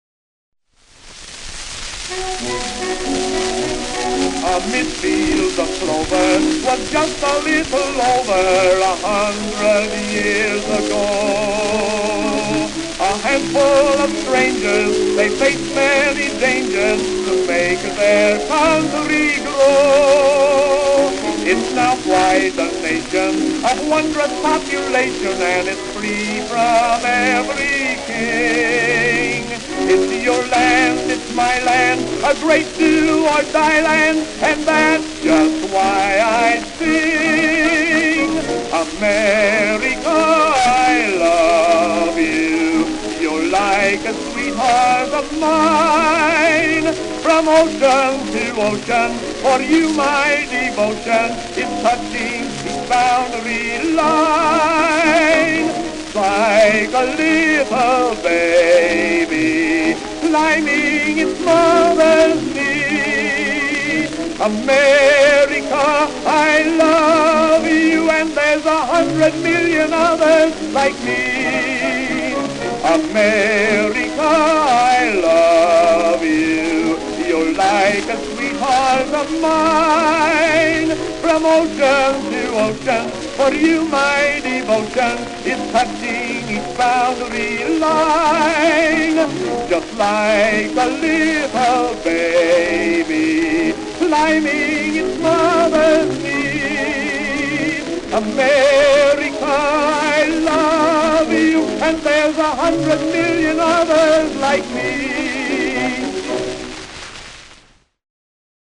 Tenor Solo